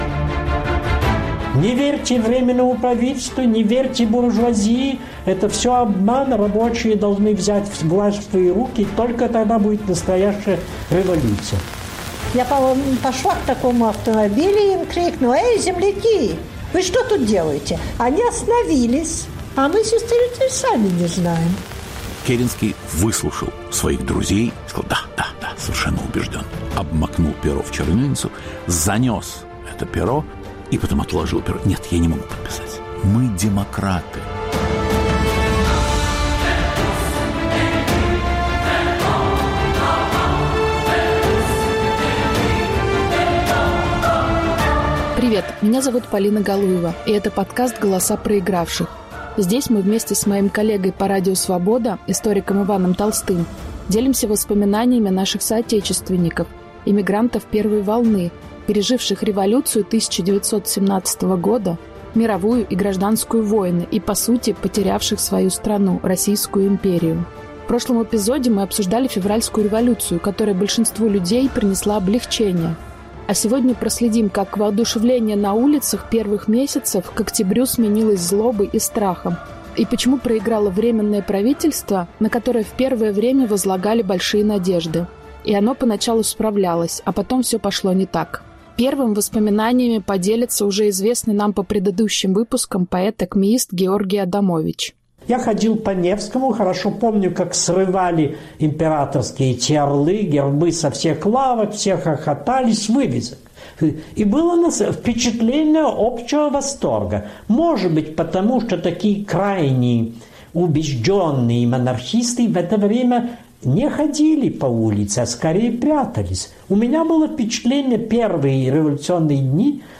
О кануне Октября - голосами очевидцев. Повтор эфира от 27 ноября 2022 года.